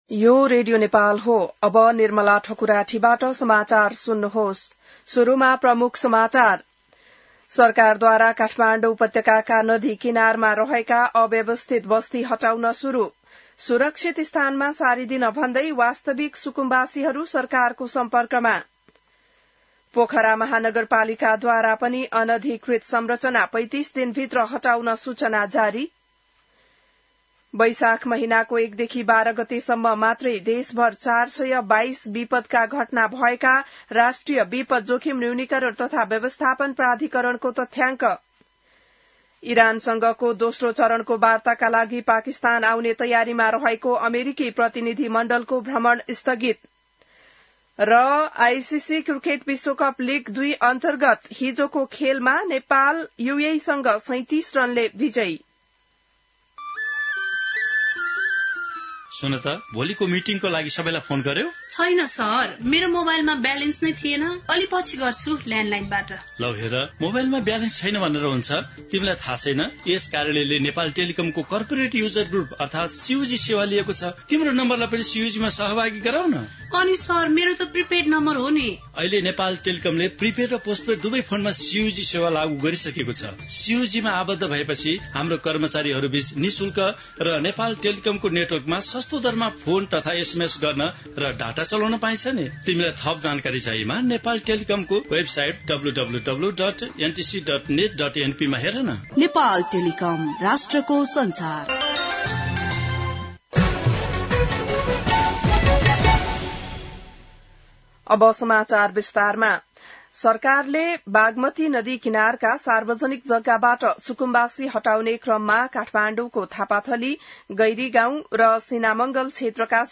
बिहान ७ बजेको नेपाली समाचार : १३ वैशाख , २०८३